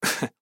Мужская ухмылка